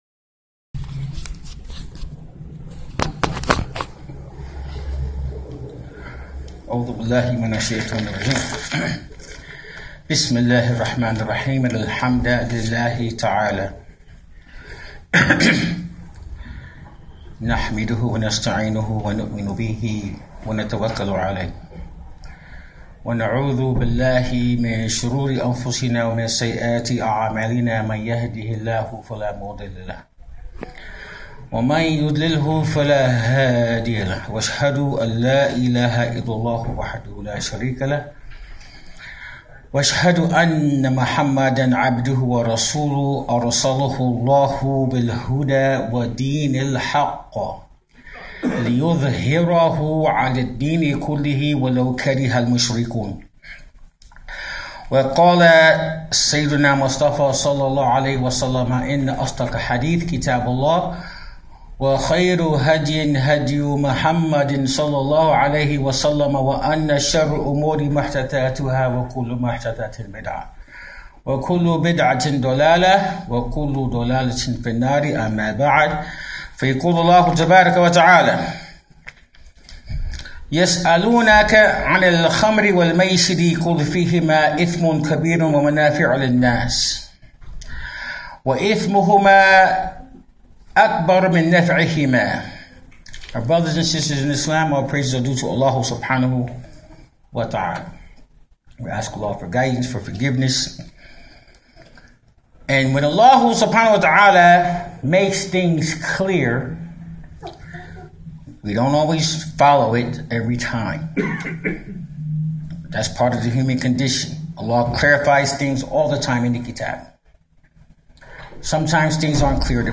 In this khutbatul Jum’ah are some advices about the scourge of drug and alcohol abuse in our communities.